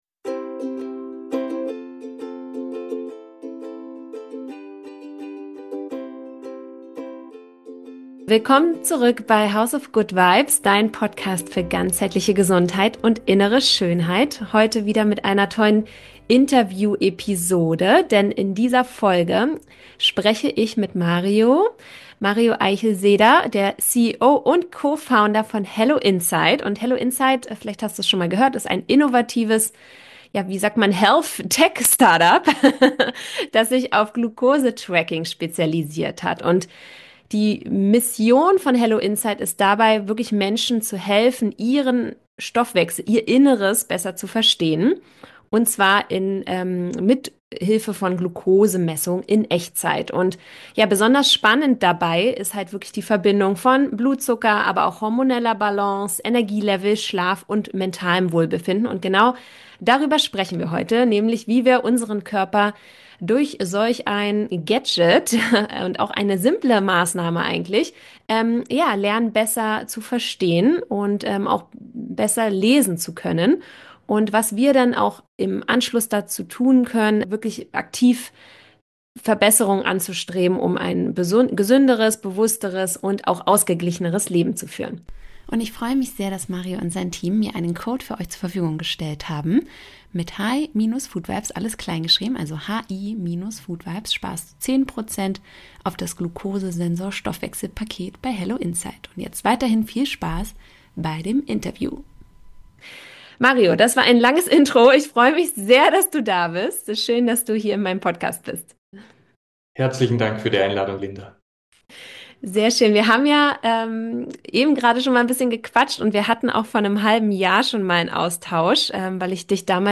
Wieder so ein inspirierendes Interview wartet in dieser Episode auf Dich.